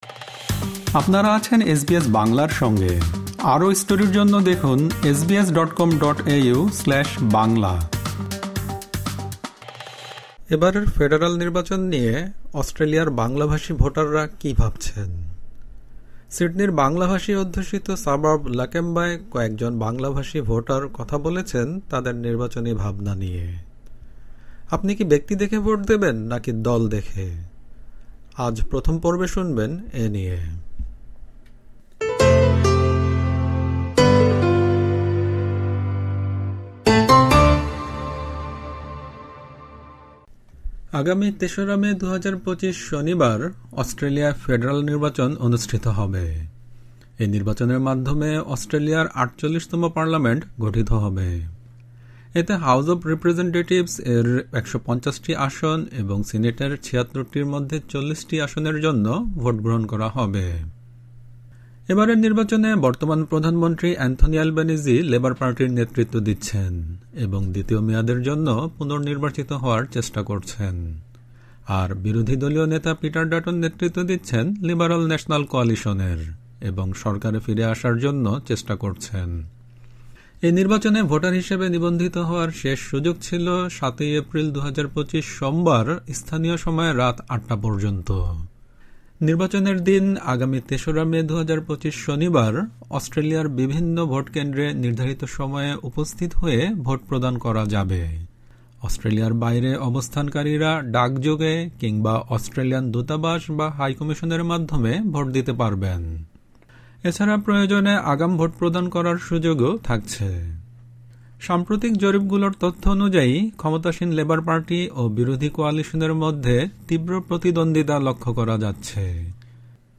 এবারের ফেডারাল নির্বাচন নিয়ে অস্ট্রেলিয়ার বাংলাভাষী ভোটাররা কী ভাবছেন? সিডনির বাংলাভাষী অধ্যুষিত সাবার্ব লাকেম্বায় কয়েকজন বাংলাভাষী ভোটার কথা বলেছেন তাদের নির্বাচনী ভাবনা নিয়ে। আপনি কি ব্যক্তি দেখে ভোট দেবেন নাকি দল দেখে?